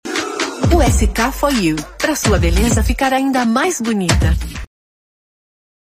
Kommerziell, Natürlich, Zuverlässig, Sanft, Corporate
Kommerziell